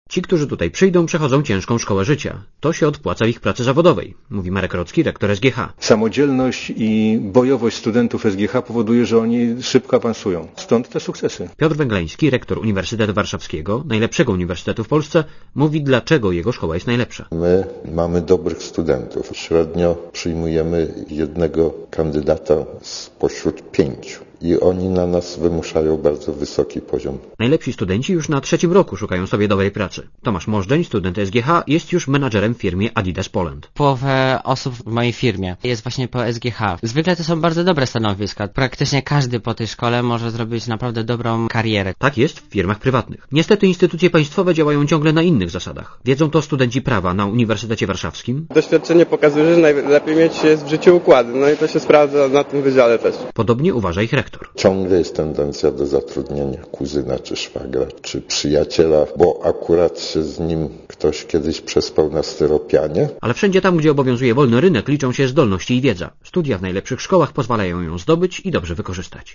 Więcej o rankingu w relacji reportera Radia Zet